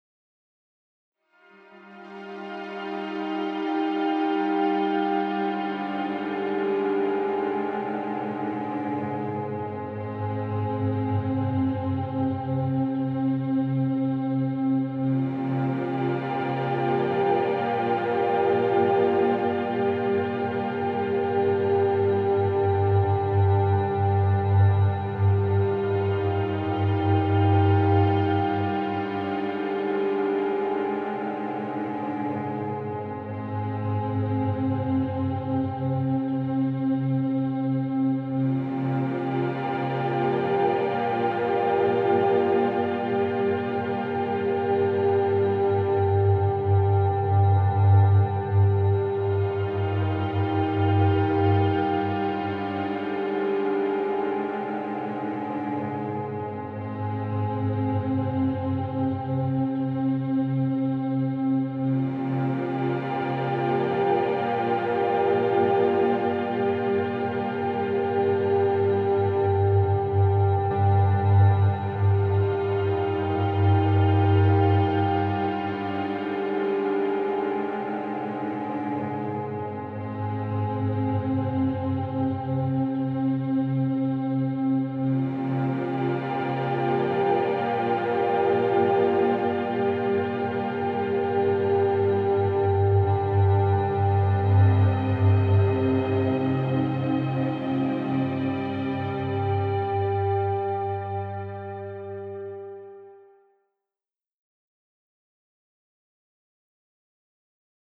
Desperately sad scenes.